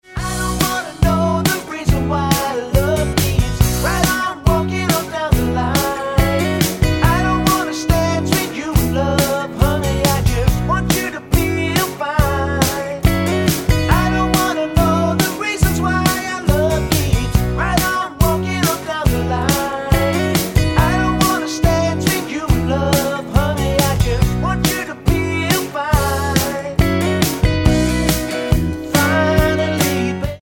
--> MP3 Demo abspielen...
Tonart:B mit Male Lead mit Chor